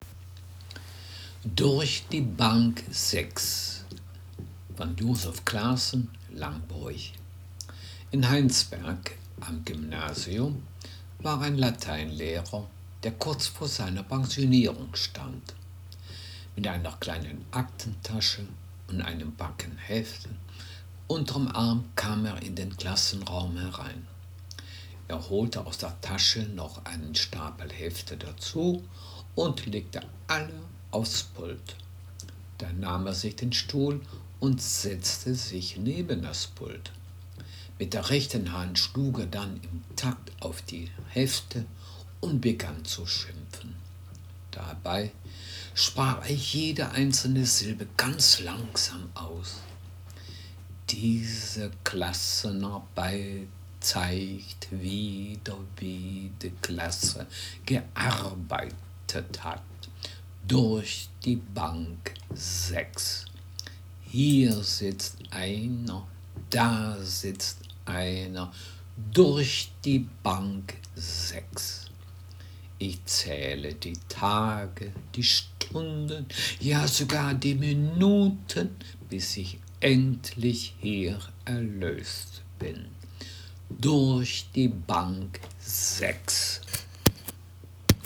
Gangelter-Waldfeuchter-Platt
Geschichte